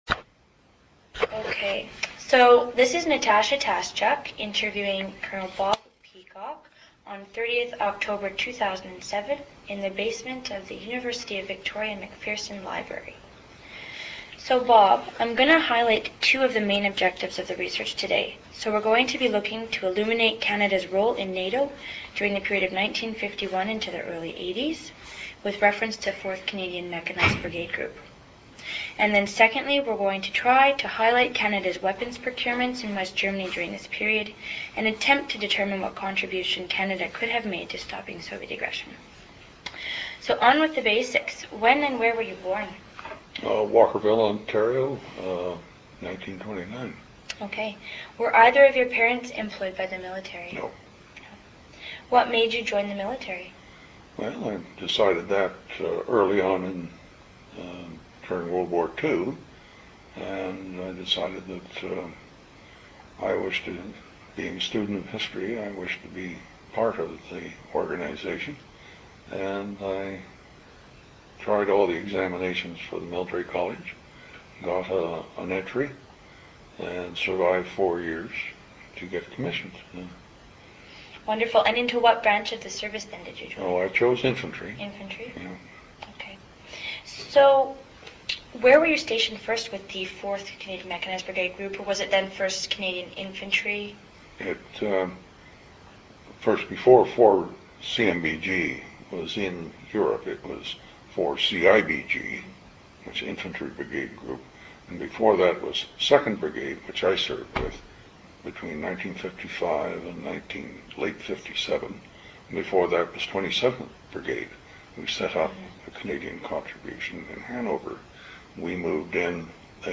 Interview took place on October 30, 2007.